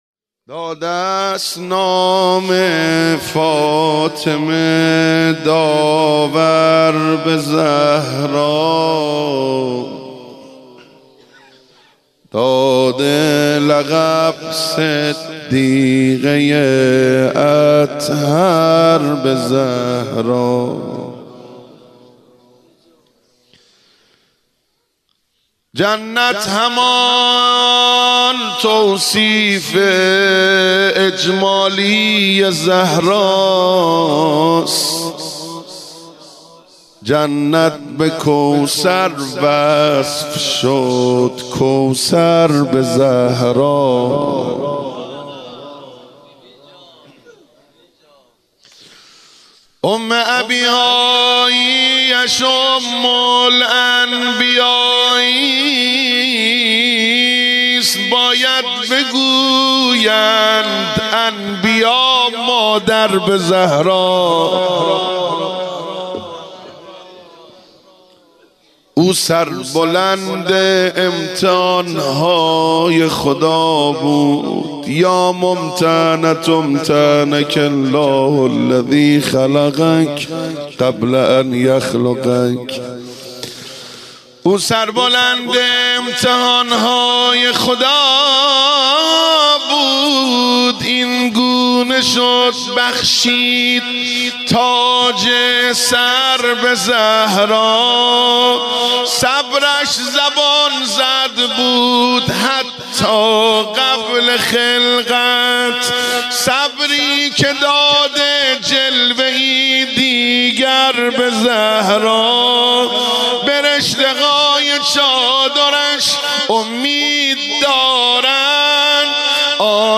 روضه خوانی فاطمیه 1396